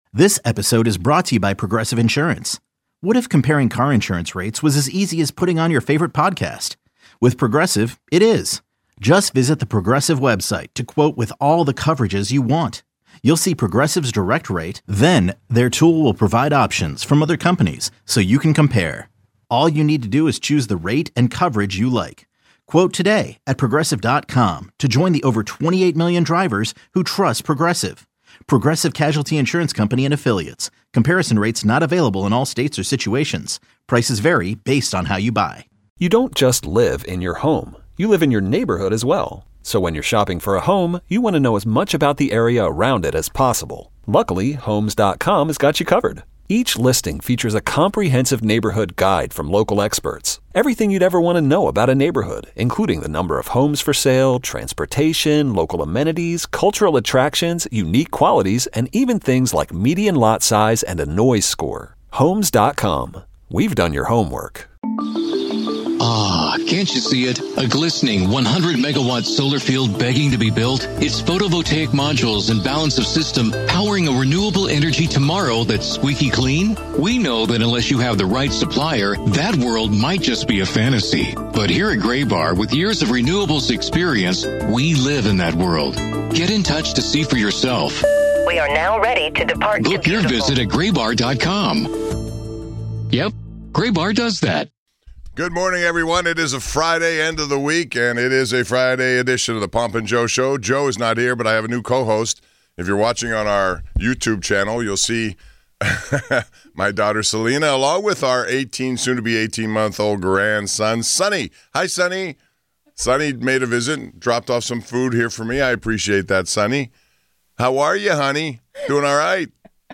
Can the NBA rebound their way to more exciting All-Star festivities like the NHL just put on? Numerous callers share their thoughts.